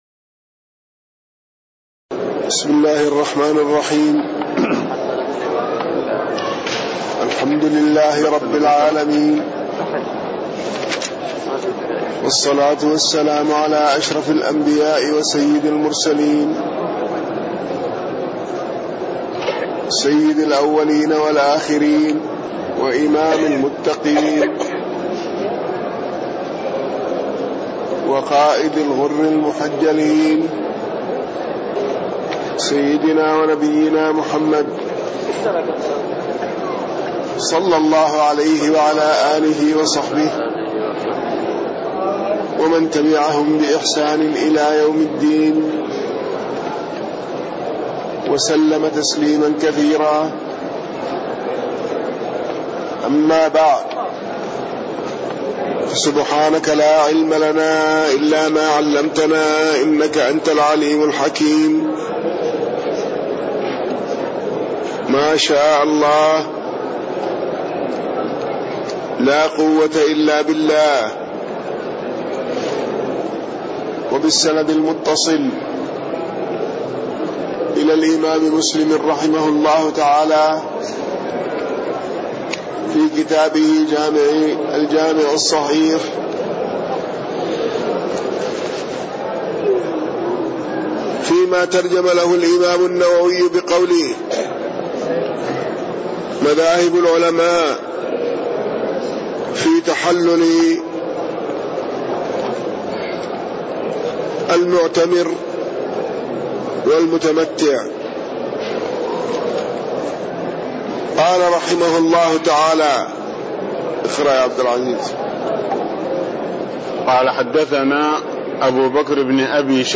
تاريخ النشر ١١ شوال ١٤٣٣ هـ المكان: المسجد النبوي الشيخ